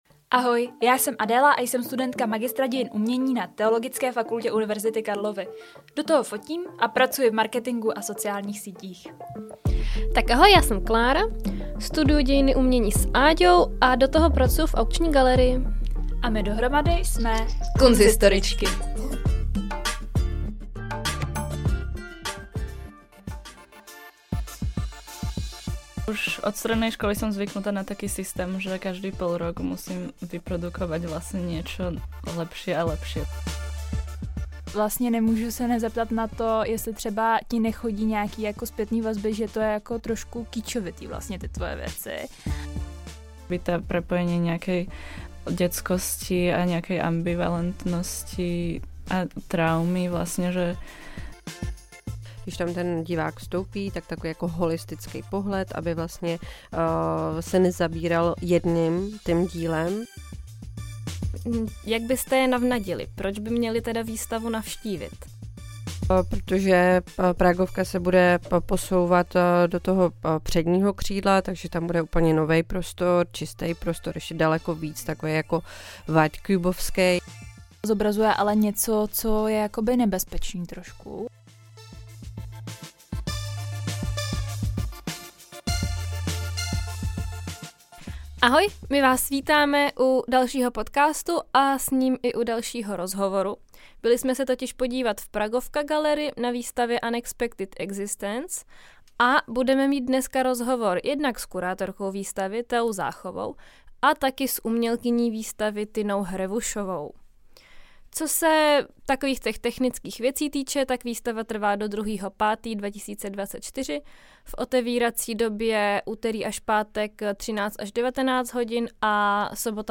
Poslechněte si zajímavý rozhovor a tip na výstavu, kterou můžete navštvit až do druhého května.